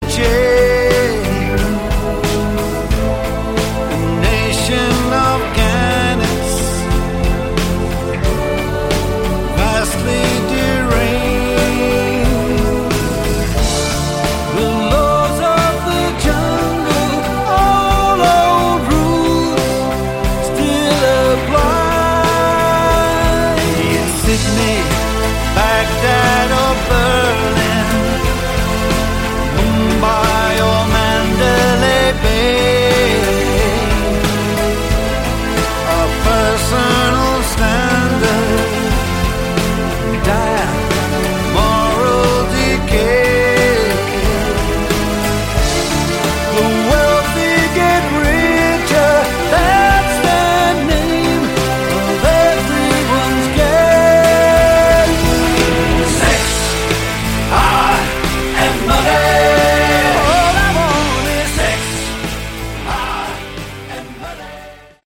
Category: Melodic Rock
vocals, bass
keyboards
guitar
cello
drums
guest harp
guest female voice